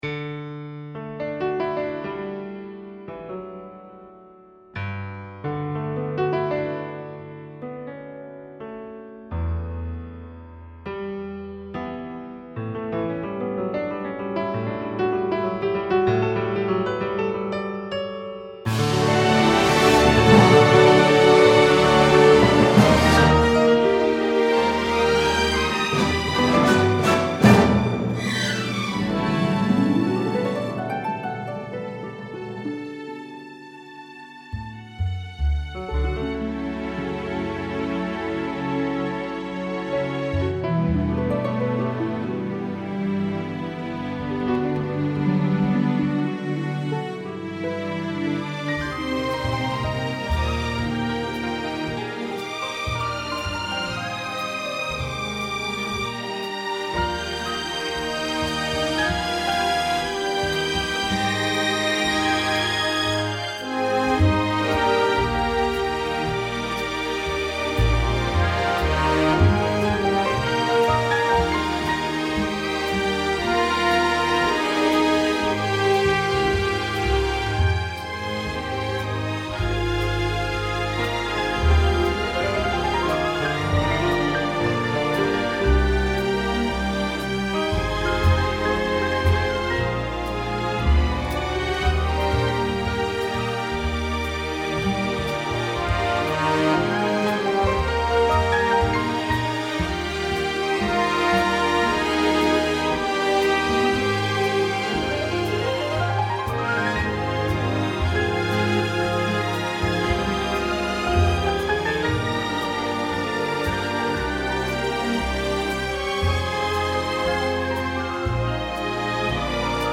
piano
banda sonora
melodía
sintonía